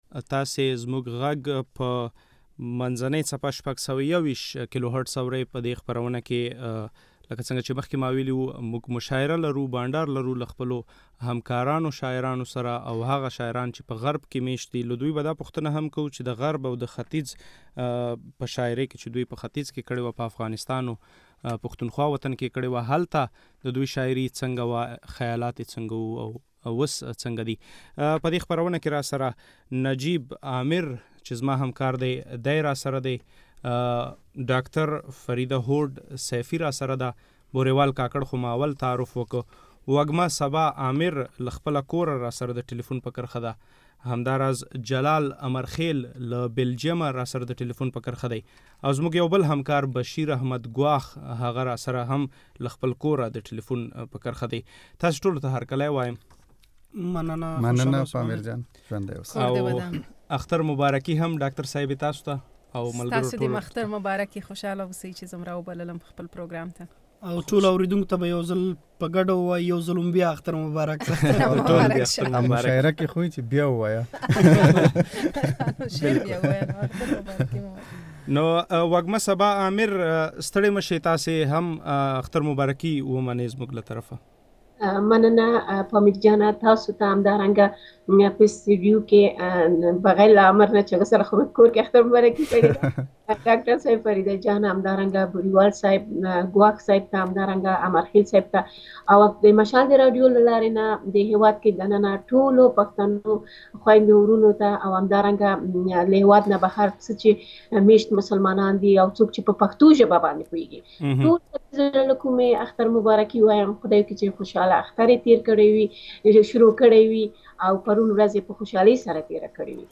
د مشال راډیو د اختر مشاعره